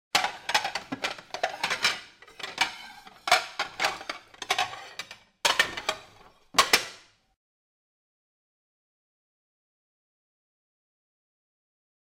02-vaisselle.mp3